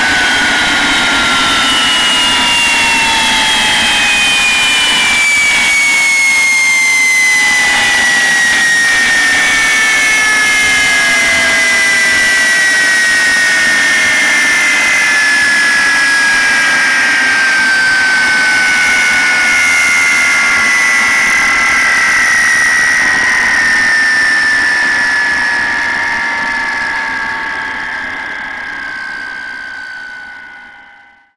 vulcan-shutdown.wav